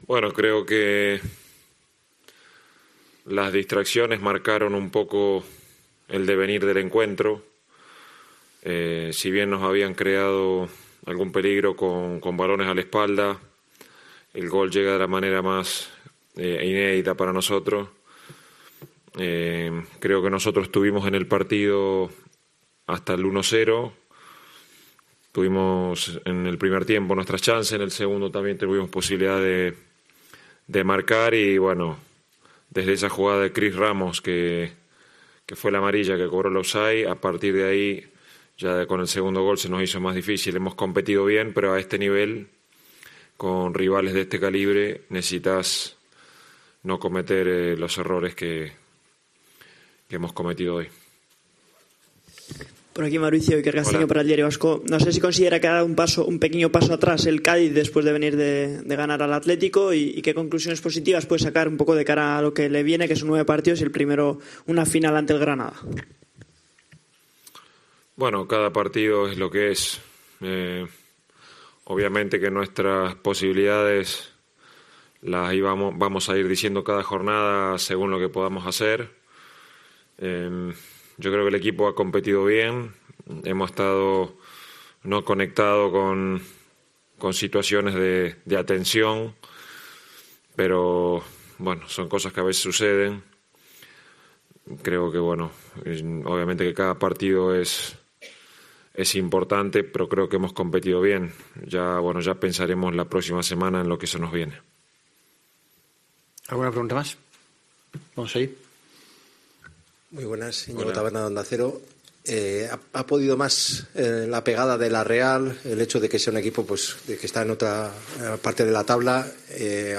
Mauricio Pellegrino tras el Real Sociedad - Cádiz
Mauricio Pellegrino expresó en más de una ocasión durante su comparecencia tras el choque frente a la Real Sociedad que su equipo compitió bien.